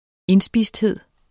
Udtale [ ˈenˌsbiˀsdˌheðˀ ]